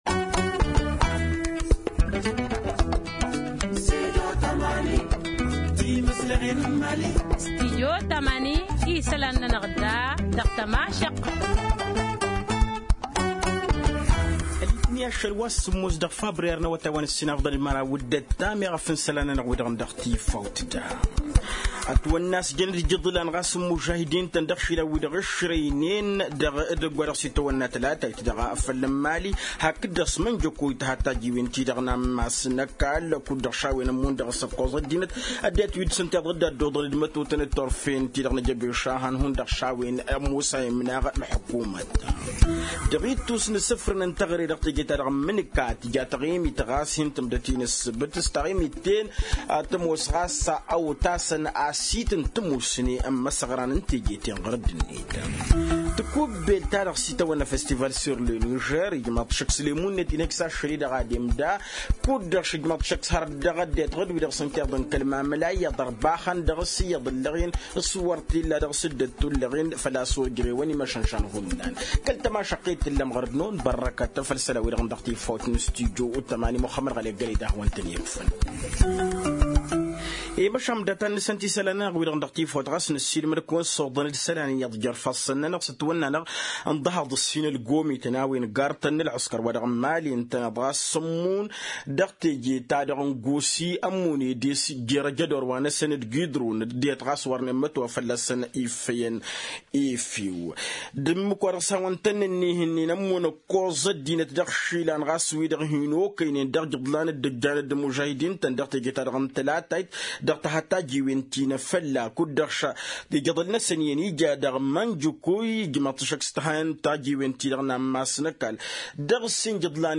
Journal en français: Télécharger